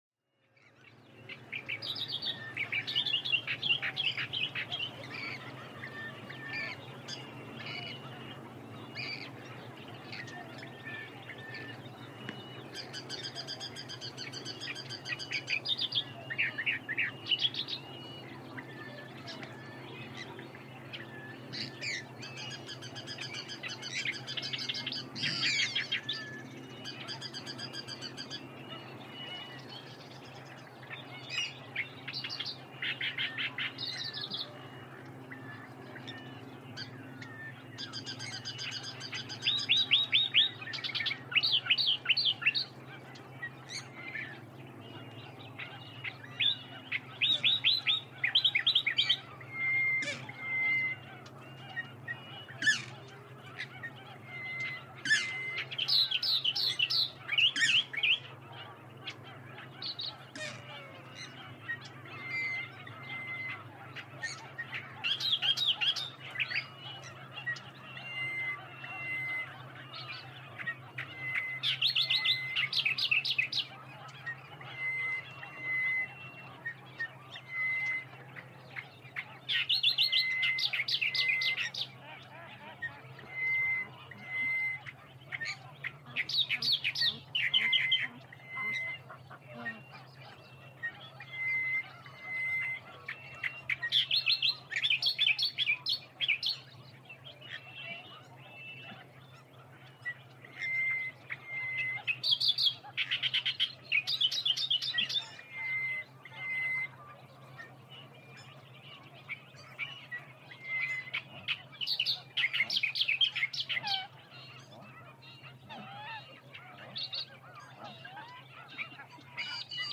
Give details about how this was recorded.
Dawn at Fivebough